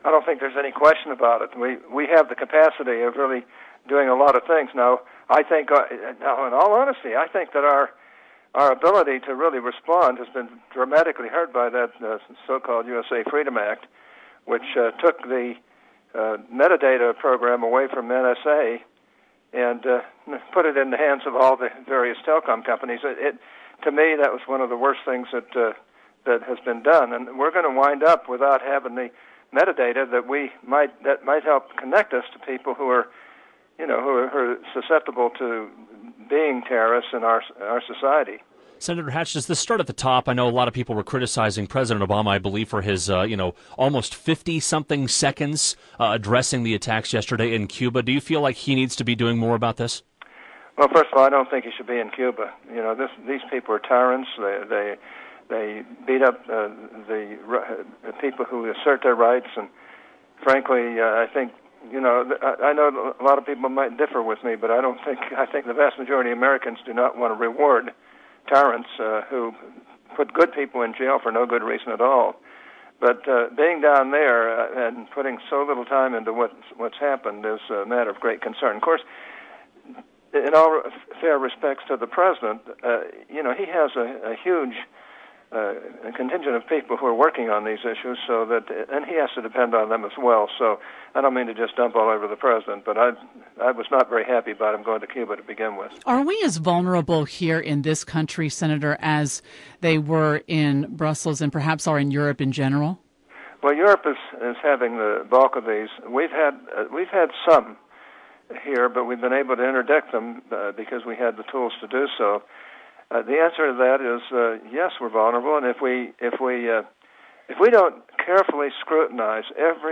He addressed the Belgium attack as well as the Utah caucuses in an interview on Utah's Morning News.